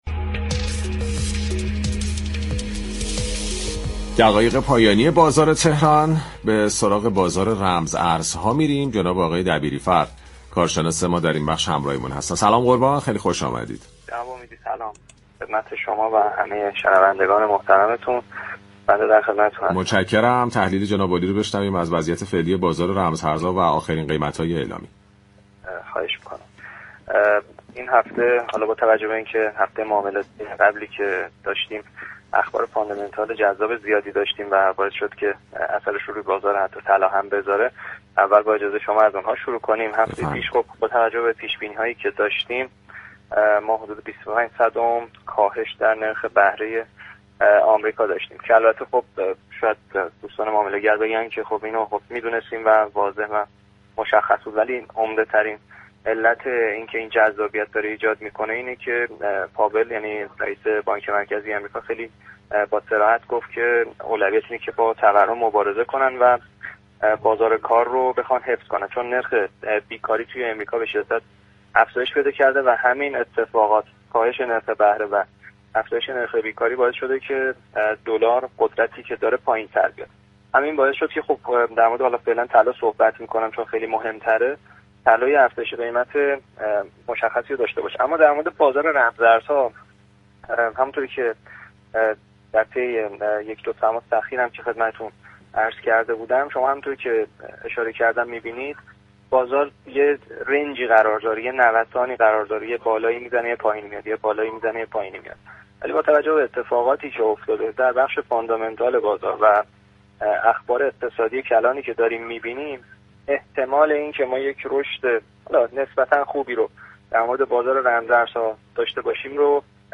برنامه«بازار تهران»، روزهای شنبه تا چهارشنبه 11 تا 11:55 از رادیو تهران پخش می‌شود.